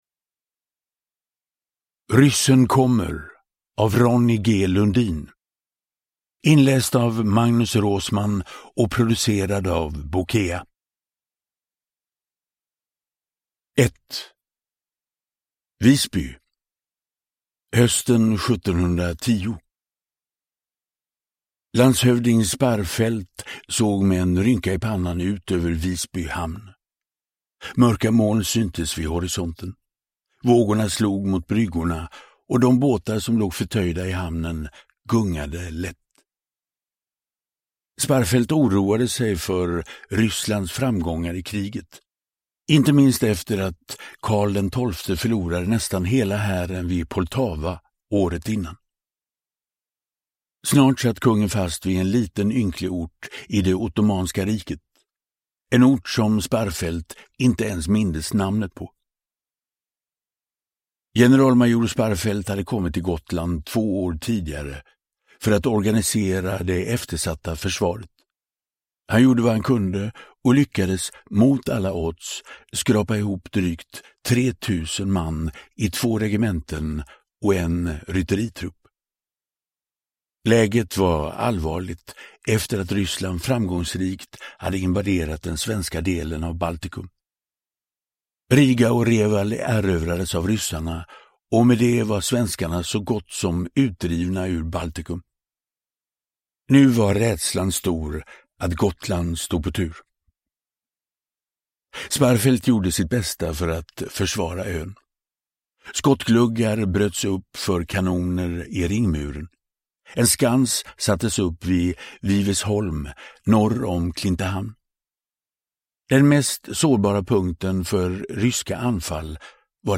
Uppläsare: Magnus Roosmann
Ljudbok